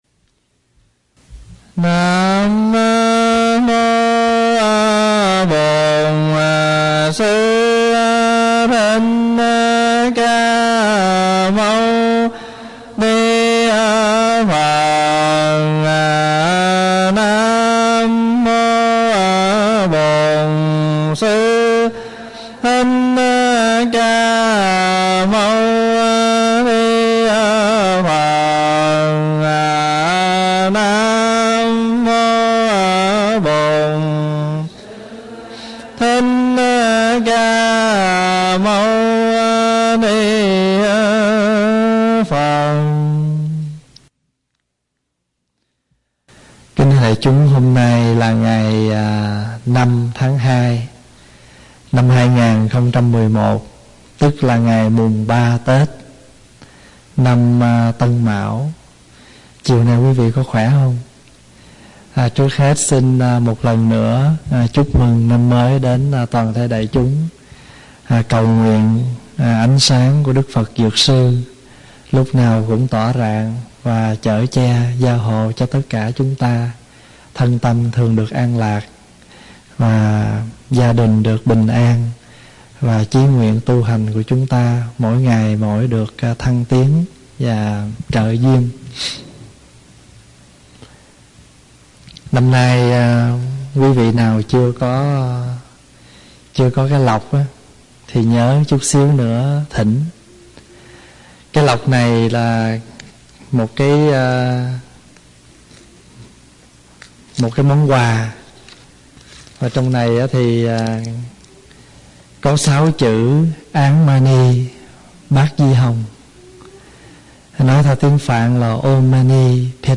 CDs - Kinh Dược Sư - Các Băng Giảng CD - Tu Viện Trúc Lâm - Viện Phật Học Edmonton